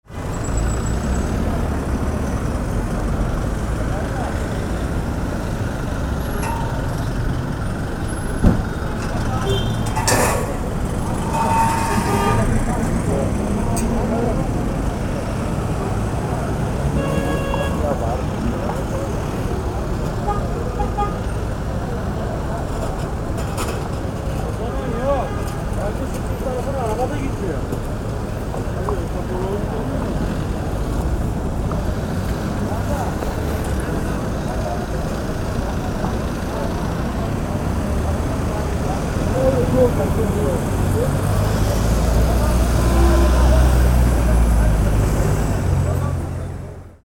Busy-istanbul-street-atmosphere-urban-background-noise.mp3